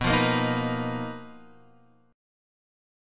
BM7b5 chord {7 8 8 8 x 7} chord